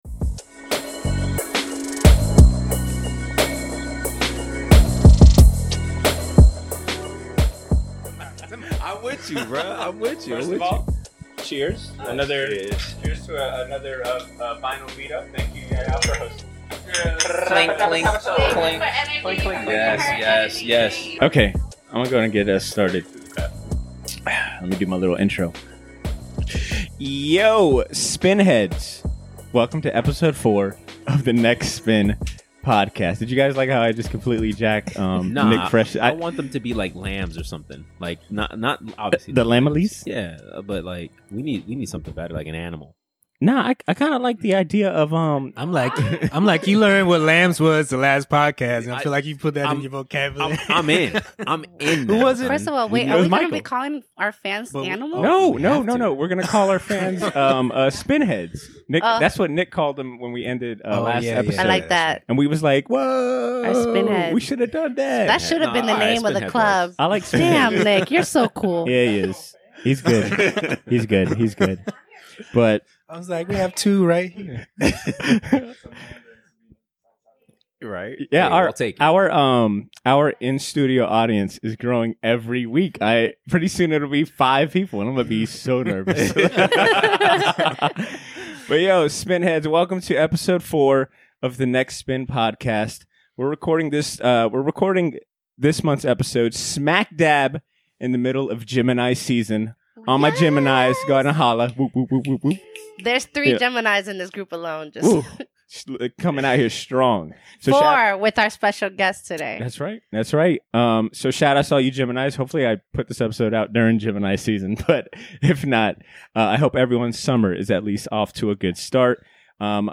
Recorded during last month's vinyl meet-up